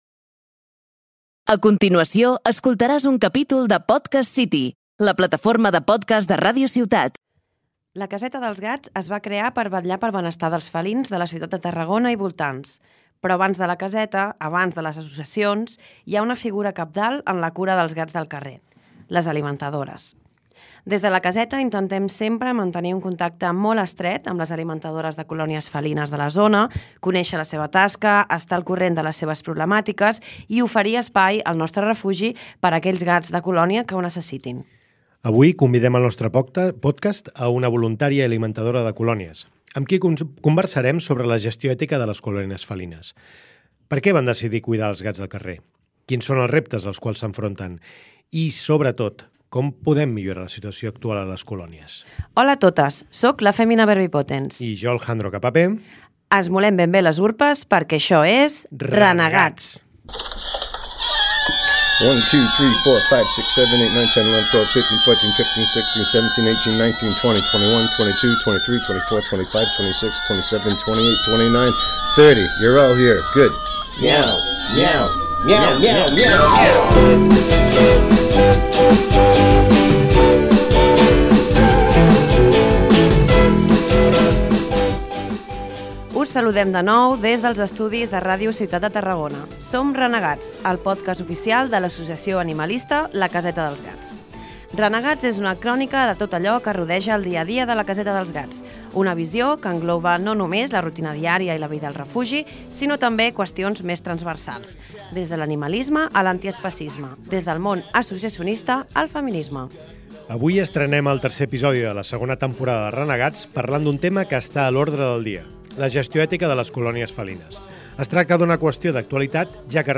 Avui convidem al nostre pòdcast a dues voluntàries i alimentadores de colònies, amb qui conversarem sobre la gestió ètica de les colònies felines.